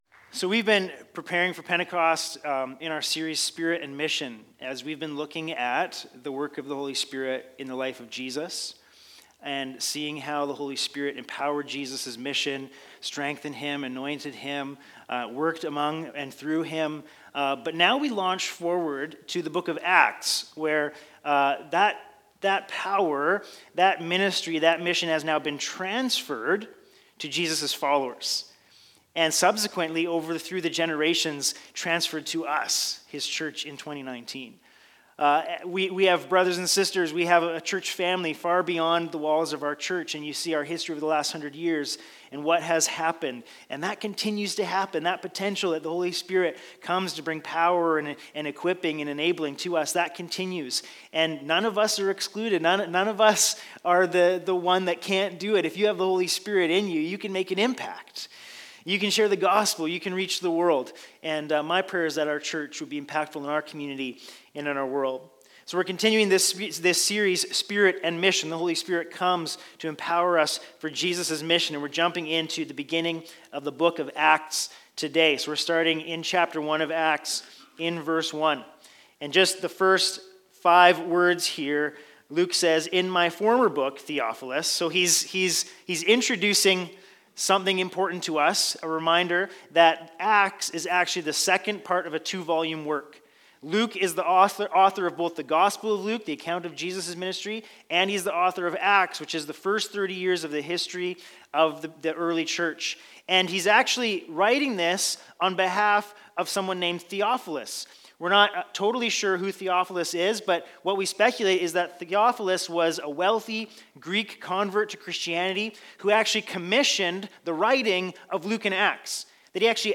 Sermons | Bethel Church Penticton